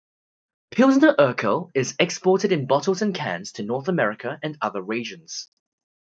Pronounced as (IPA) /ˈbɑtl̩z/